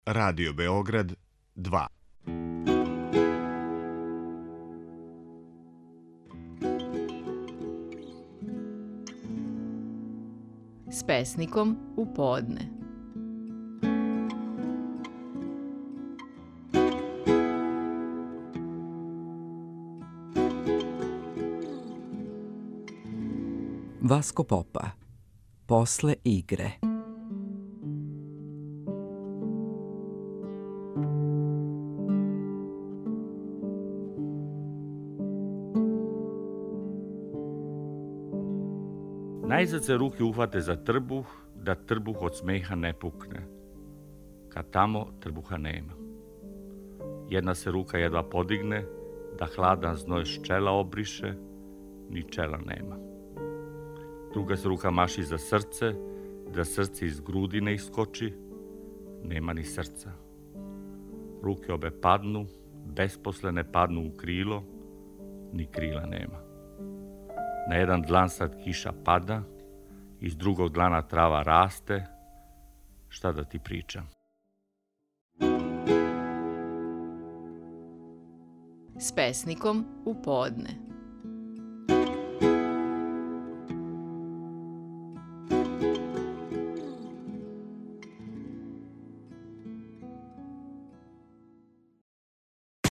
Стихови наших најпознатијих песника, у интерпретацији аутора.
Васко Попа говори своју песму „После игре".